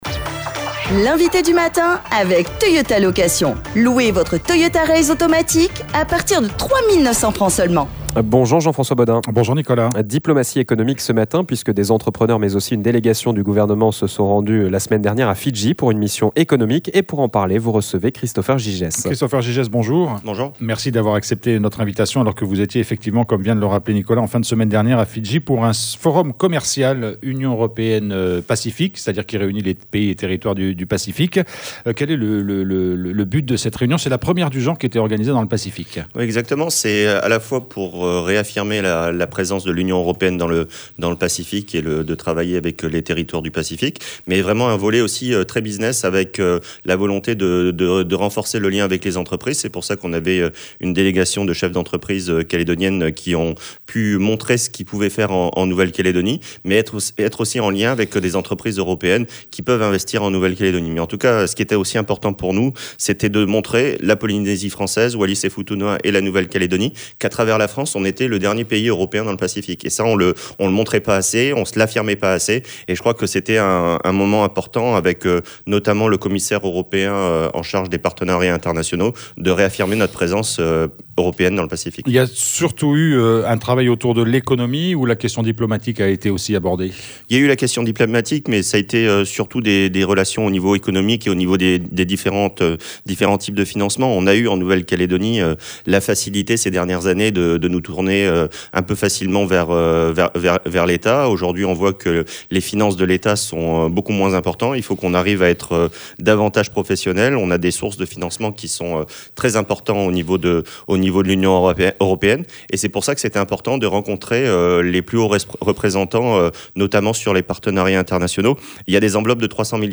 Le porte-parole du gouvernement, en charge notamment de l'économie, revient sur plusieurs actualités : la mission économique menée à Fidji, mais aussi les dernières annonces du ministre des outre-mer, Manuel Valls.